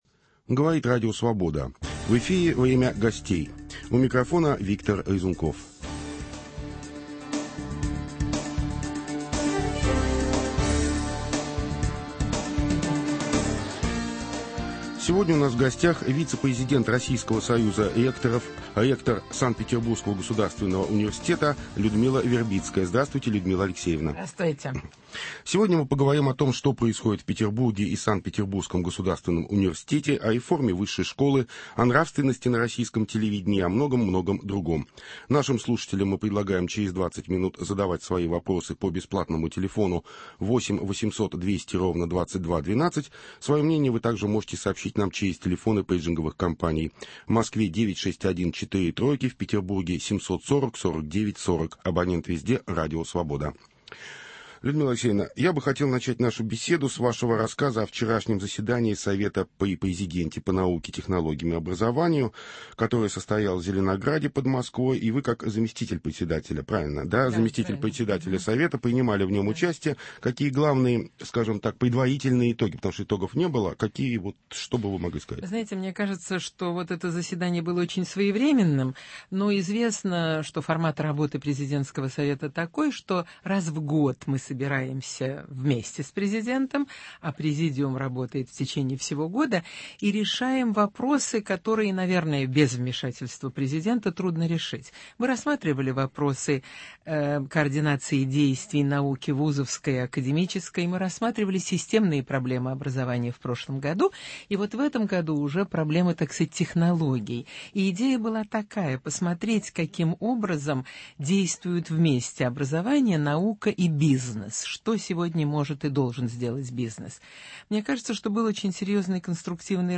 Кто и зачем дискредитирует петербургских деятелей науки и культуры? Об этом и многом другом - в беседе с вице-президентом Российского союза ректоров, ректором Санкт-Петербургского государственного университета Людмилой Вербицкой